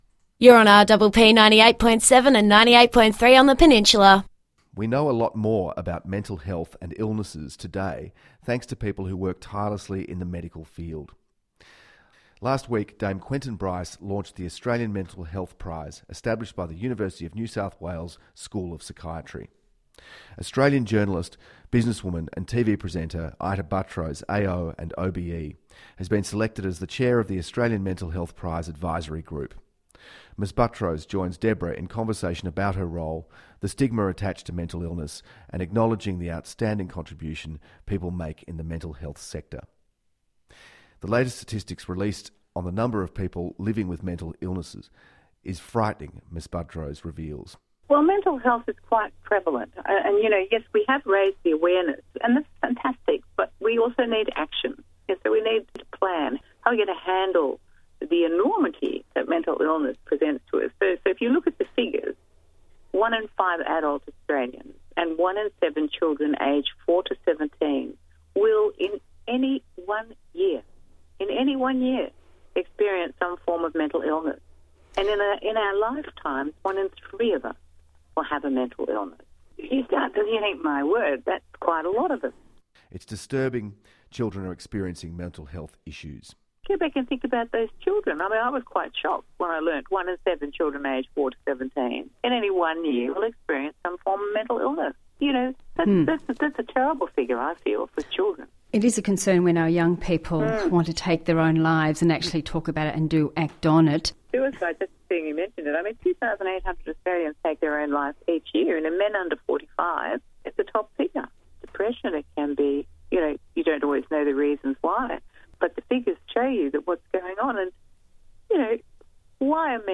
Also interviewed for the piece were..
Ita Buttrose – Journalist, business woman, personality and Author – Chair of Australian mental health prize